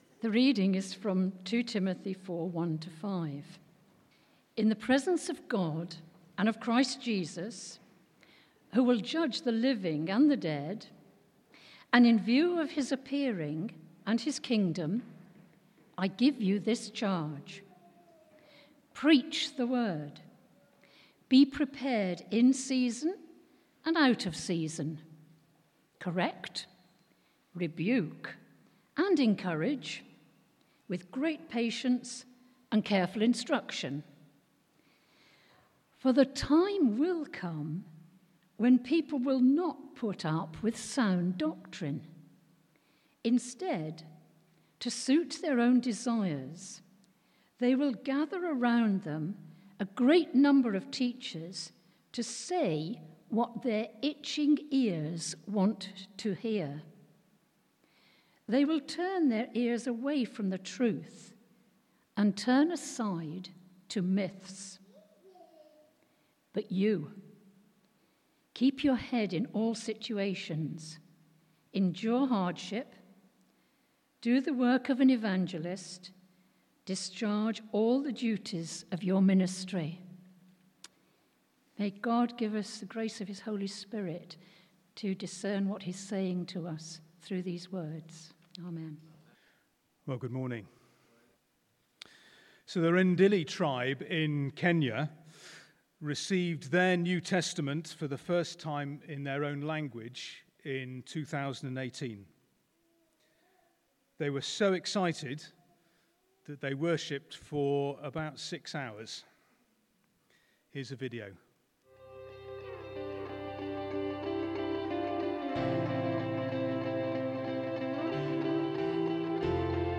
Preacher
Passage: 2 Timothy 4.1-5 Service Type: Sunday 11:00am